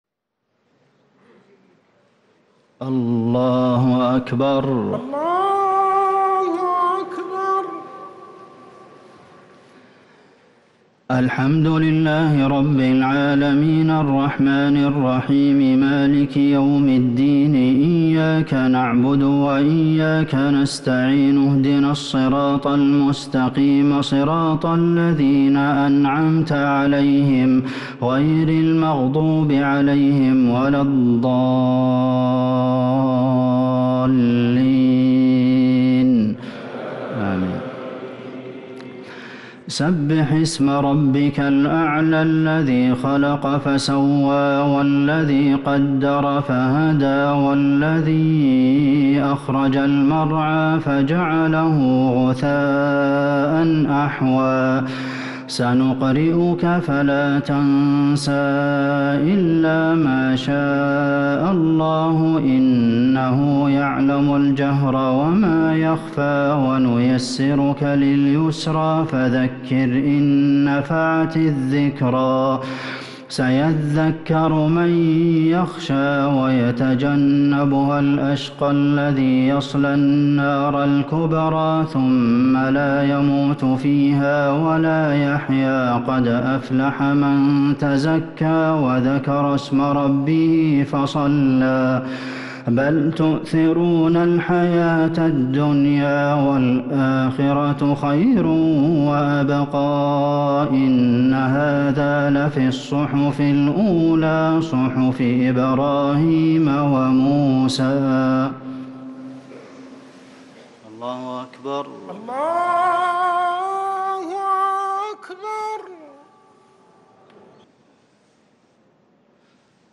صلاة التراويح ليلة 3 رمضان 1444 للقارئ عبدالمحسن القاسم - التسليمتان الأخيرتان صلاة التراويح
تِلَاوَات الْحَرَمَيْن .